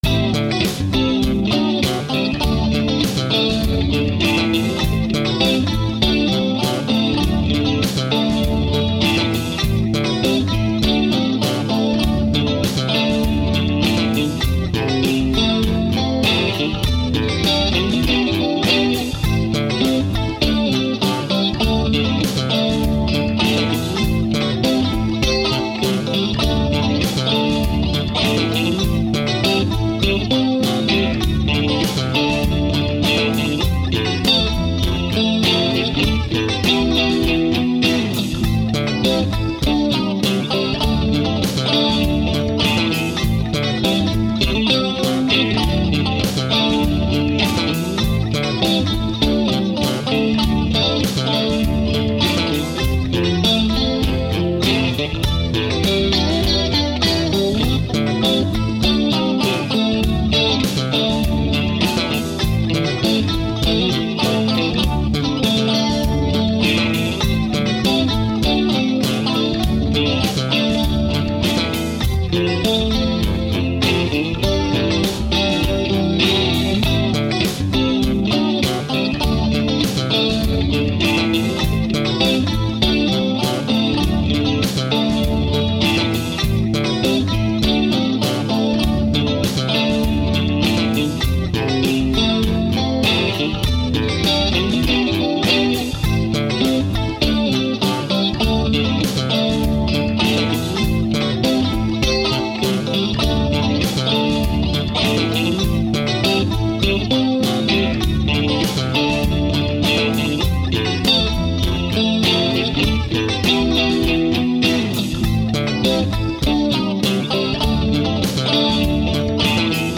While experimenting with different rhythm parts, I ran across a really funky bassline, and it turned out to be perfect. Then I added some funky drums, layered on a walking double-stop run, pulled out the Goldtop and started to JAM!!!
Aracom 22 Watt Prototype  with 6V6’s – freakin’ awesome amp! (Sorry, can’t say any more at this time.)
Strat for both rhythm parts.
funkaliciousjamlow.mp3